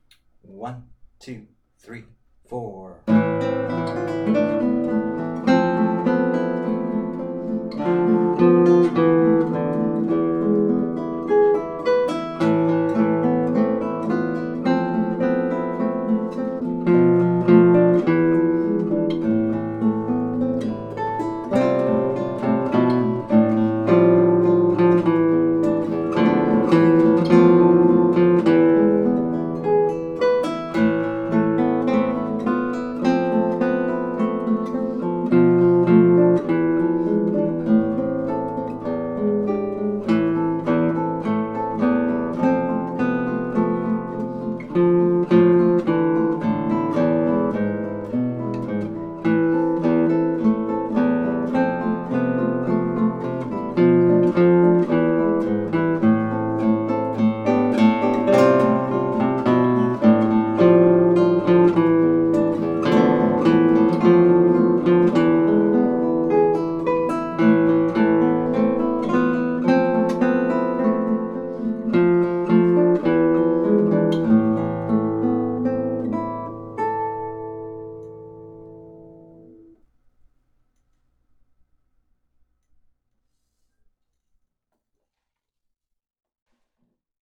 Kojo no Tsuki | Melody and chords